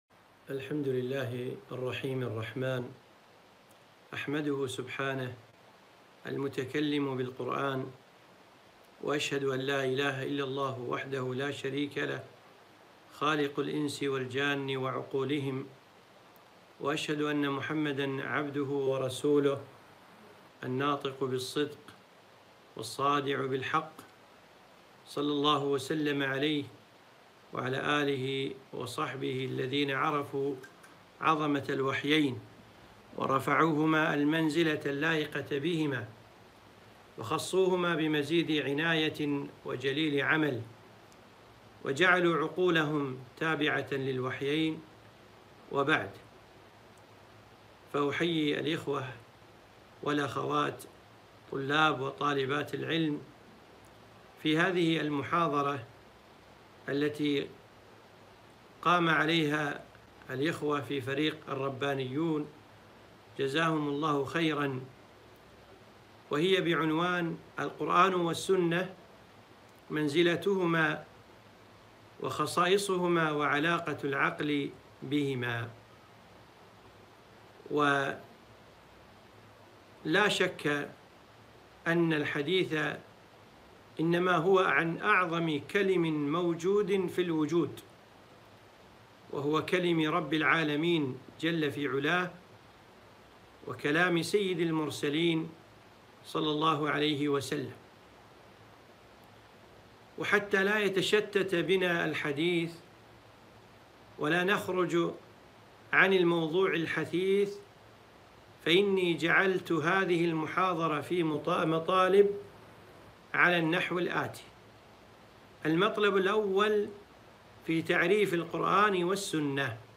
محاضرة - القرآن والسنة ومنزلتهما وخصائصهما وعلاقة العقل بهما